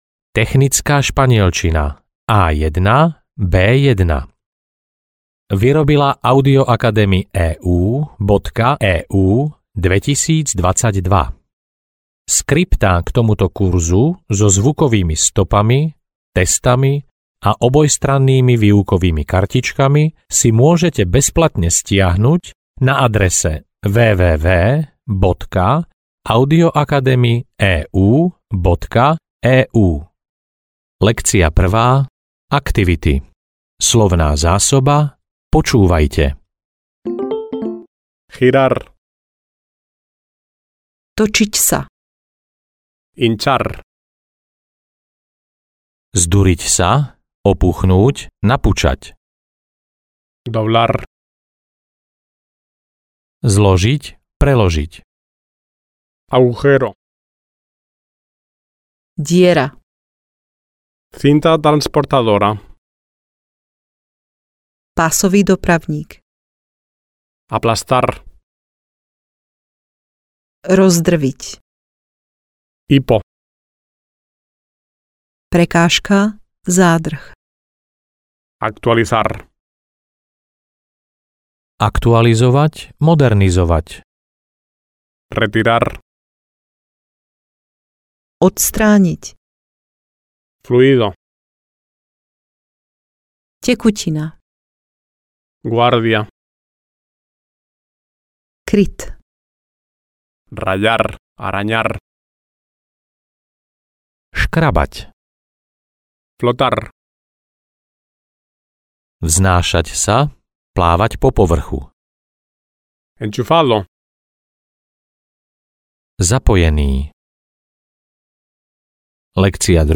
Technická španielčina A1-A2 audiokniha
Ukázka z knihy